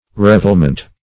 Revelment \Rev"el*ment\, n. The act of reveling.